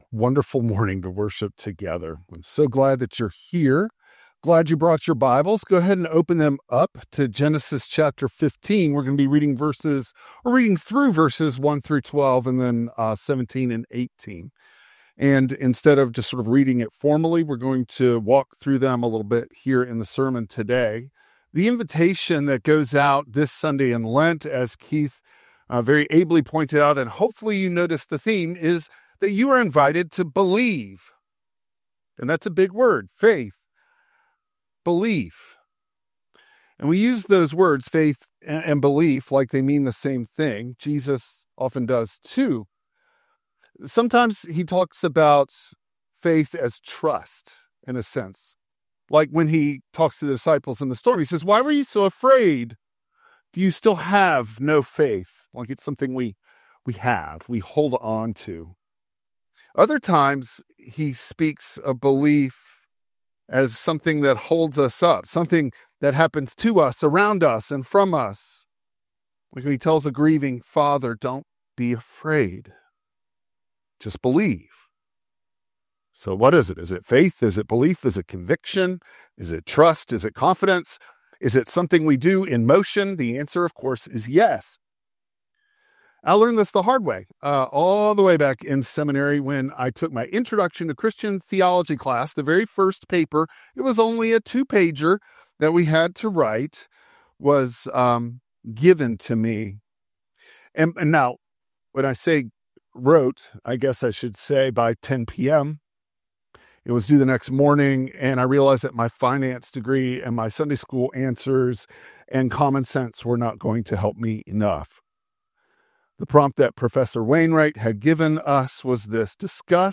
17-19 Service Type: Traditional Service Faith isn’t certainty—it’s movement.